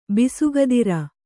♪ bisugadira